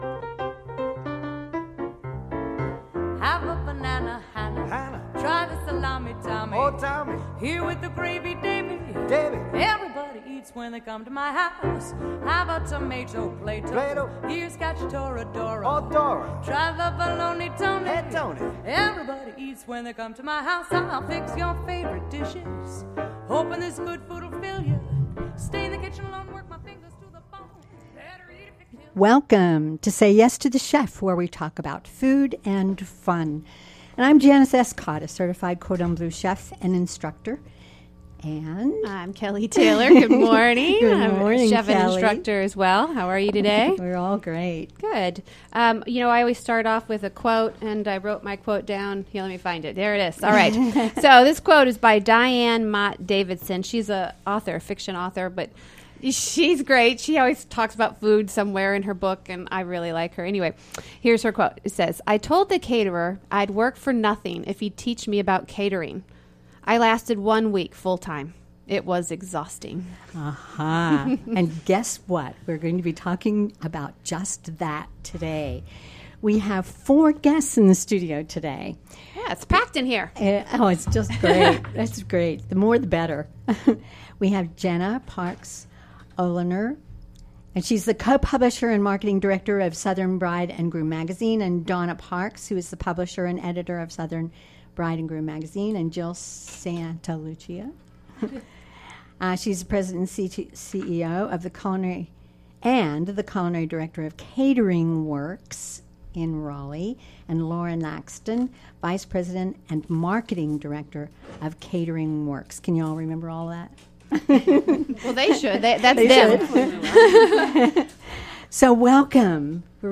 Catering Works interviewed on WCOM FM 103.5. Click for the podcast